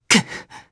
Arch-Vox_Damage_jp_02.wav